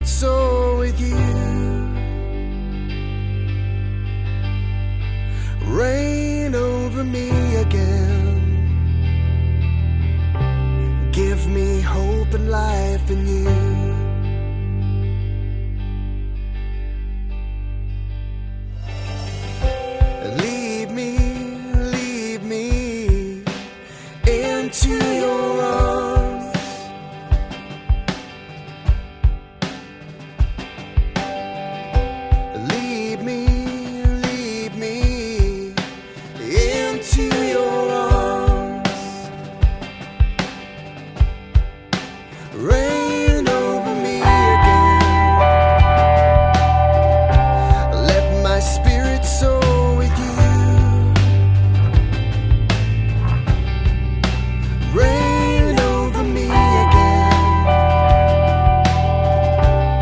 New Worship from Northern Ireland
• Sachgebiet: Praise & Worship